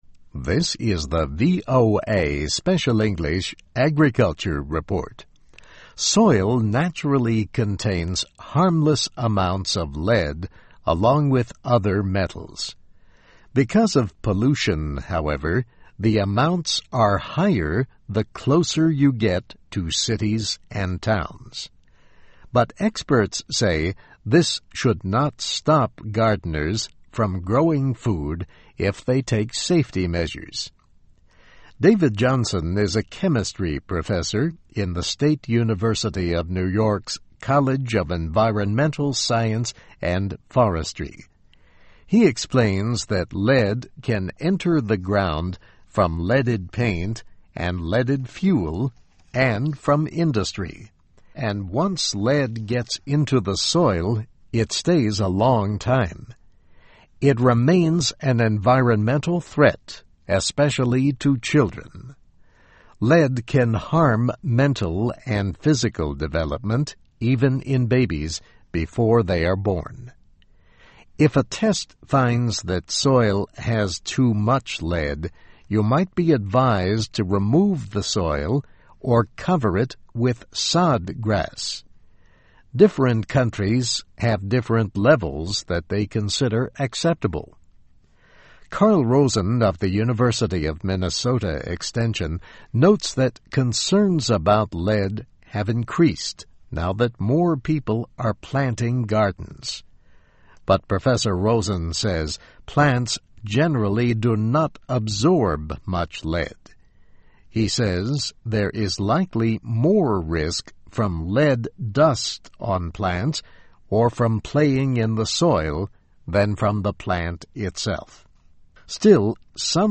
Topic: The first step is to test soil to see if there is a danger. Transcript of radio broadcast.